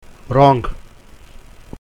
Nasals